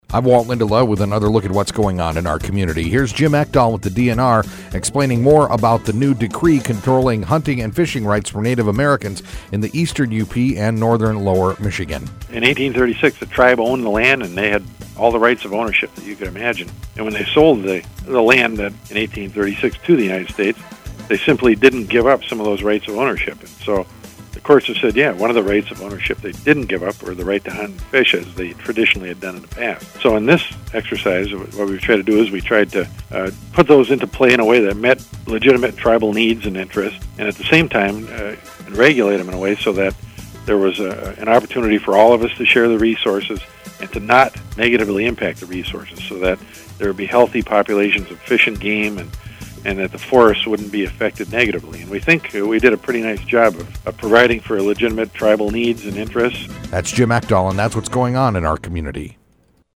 Discussion of the new 2007 treaty covering hunting and fishing rights of native american tribes in the Eastern UP and western lower peninsula of Michigan. Click HERE To Listen To Interview as Mp3 Click To Submit Press Releases, News, Calendar Items, and Community Events to mediaBrew radio stations WFXD, WKQS,...